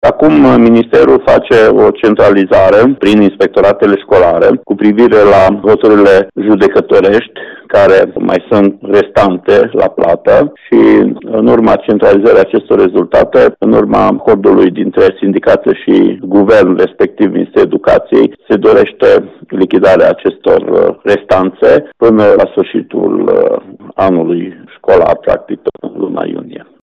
Inspectorul şcolar general al judeţului Mureş, Ştefan Someşan, a precizat că profesorii îşi vor putea primi banii până la sfârşitul acestui an şcolar: